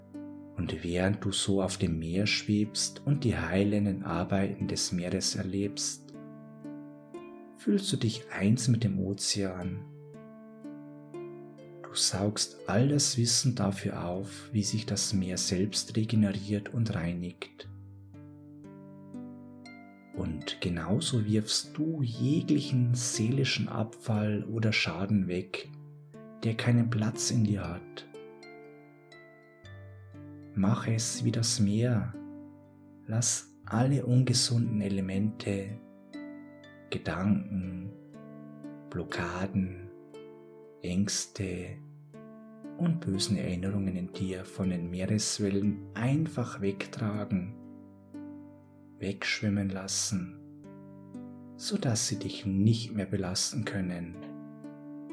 Erleben Sie die Kraft der Entspannung und Blockadenlösung mit unserer geführten Hypnose